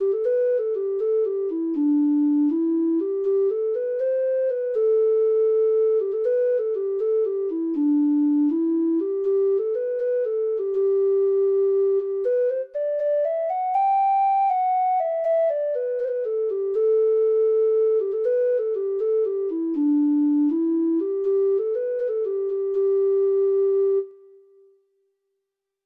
Treble Clef Instrument version